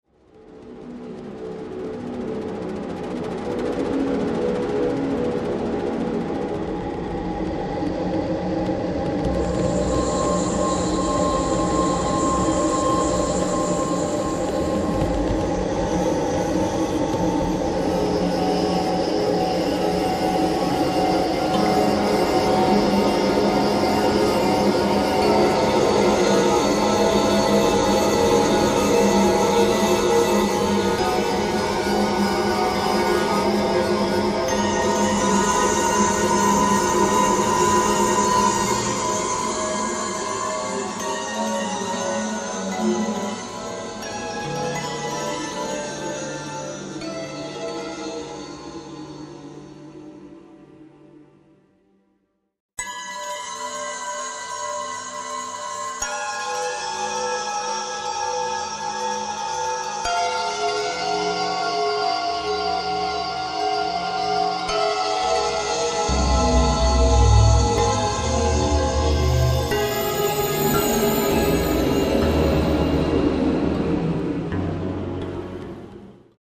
gitarre,programming
sopran- tenor- baritonsaxophone